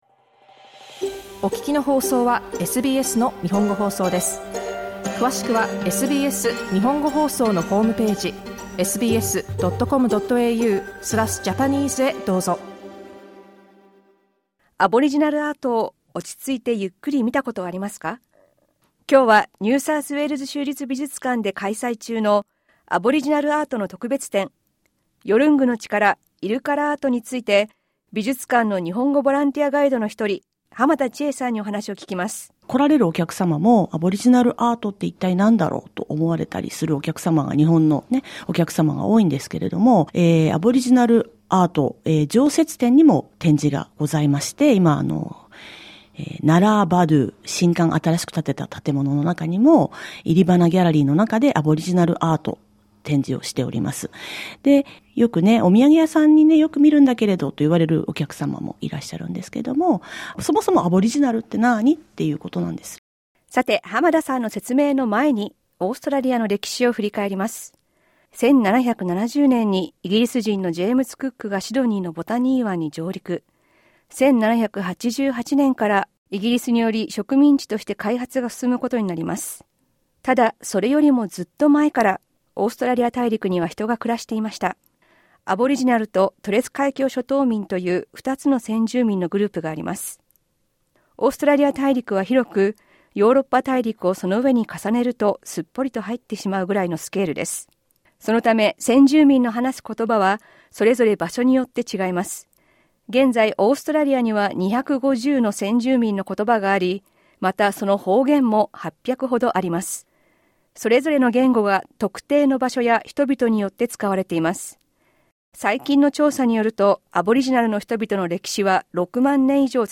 詳しくは日本語インタビューからどうぞ。